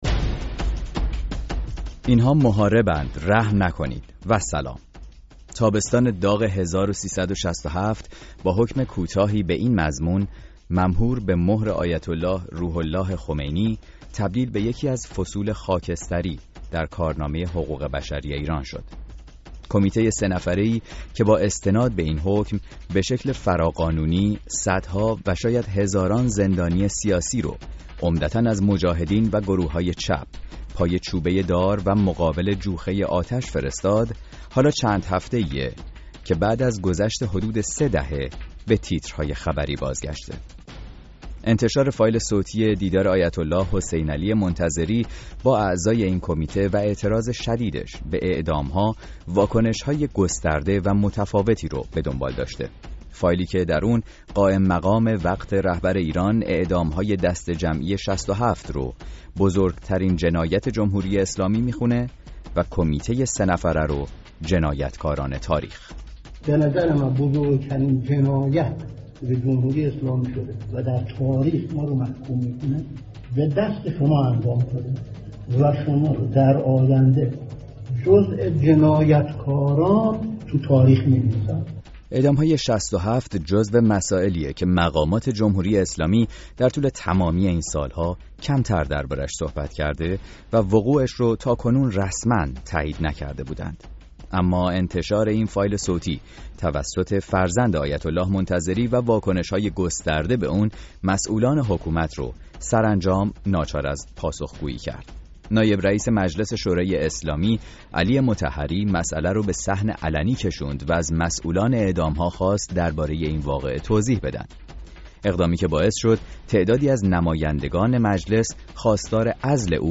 تابستان ۶۷ با حکم کوتاهی به این مضمون، ممهور به مُهر آیت الله روح الله خمینی تبدیل به یکی از فصول خاکستری در کارنامه حقوق بشری ایران شد. این برنامه میزبان یکی از فعالان سیاسی است که این تابستان پرحادثه را در زندان اوین محبوس بود.